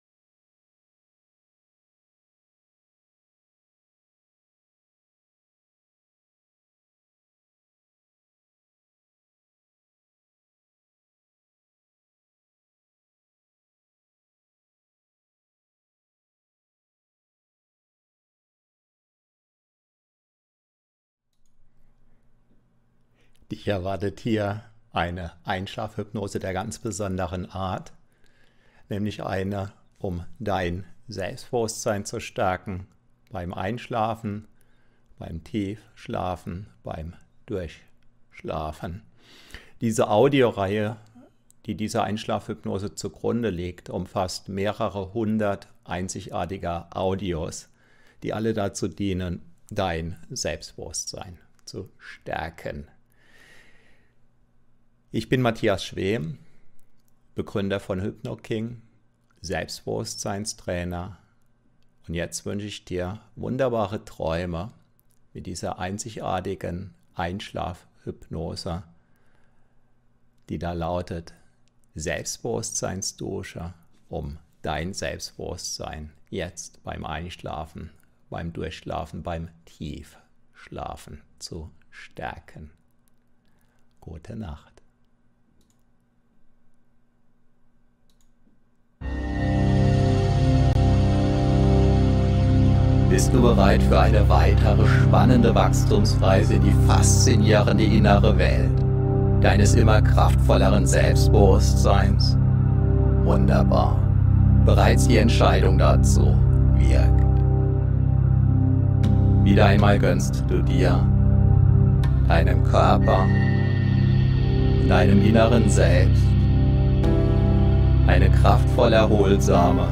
Hypnose Einschlafen Durchschlafen: Selbstbewusstseins-Dusche fürs wachsende Selbstbewusstsein ~ HypnoKing® Hypnosen zum Einschlafen, Durchschlafen, Tiefschlafen gratis aufs Handy, Tablet & Co Podcast